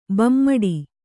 ♪ bammaḍi